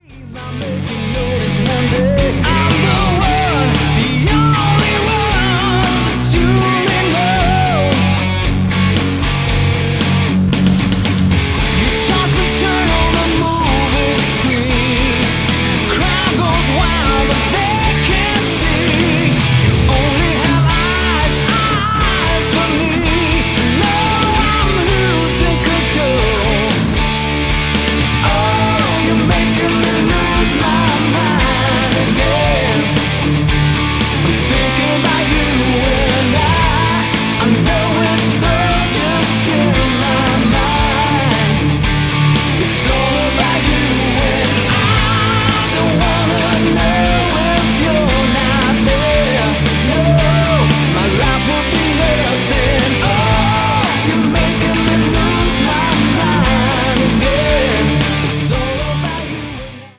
vocals
guitar
drums
bass
melodic rock